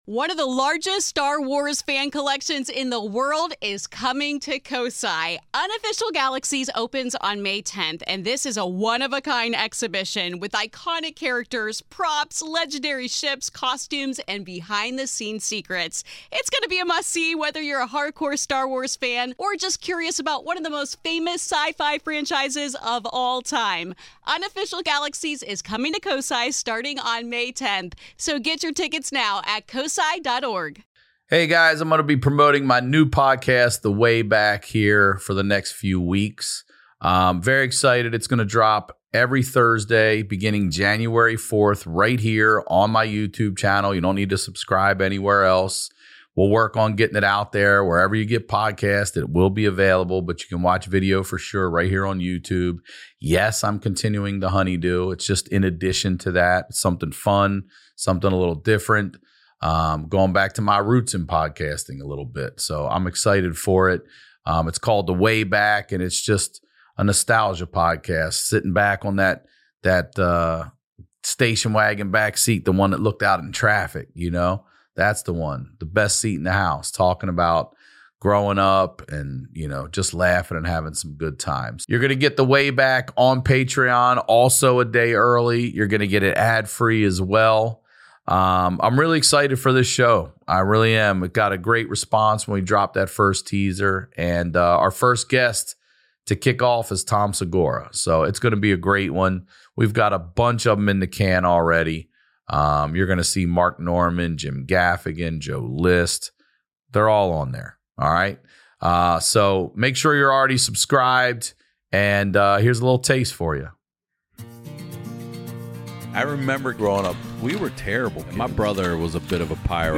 My HoneyDew this week is comedian Steve-O (Jackass, Steve-O’s Wild Ride!) Steve-O Highlights the Lowlights of his latest stand up special, Steve-O’s Bucket List.